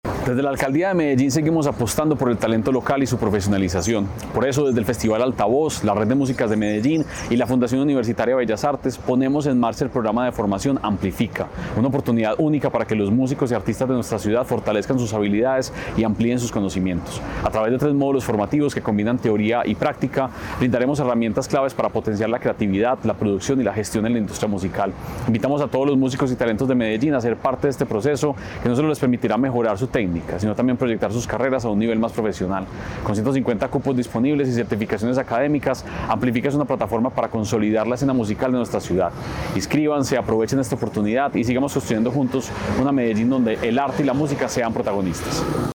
Audio Palabras de Santiago Silva, secretario de Cultura Ciudadana Este año, el Distrito de Ciencia, Tecnología e Innovación presentó una nueva oportunidad dirigida a los artistas y talentos locales interesados en profesionalizar sus carreras musicales.